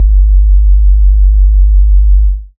Sub Wobble G2.wav